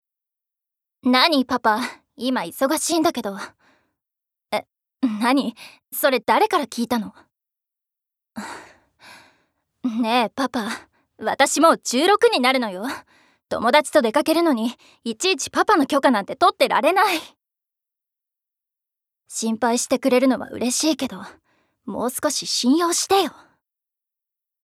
ボイスサンプル
セリフ６